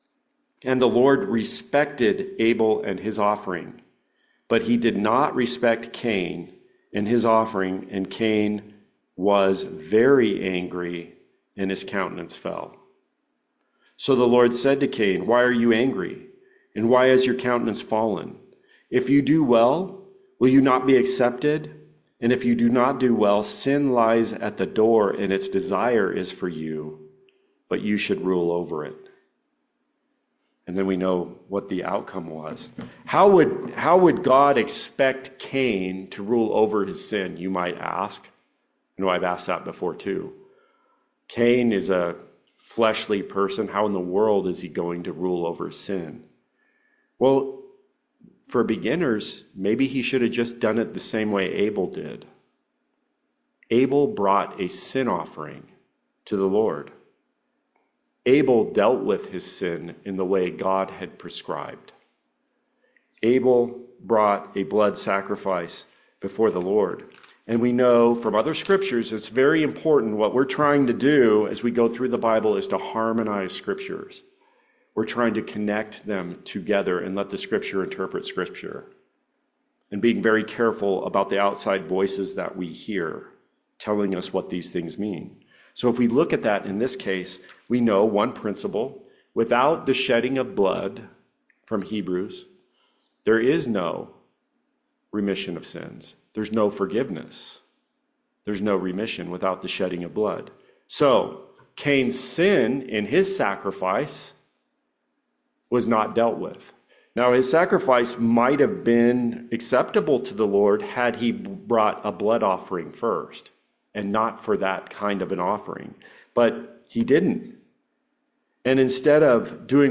This is the second part of a sermon from the Book of Jude on the Way of Cane. This explores the deadly outcome of trying to deal with sin our own way instead of God’s way.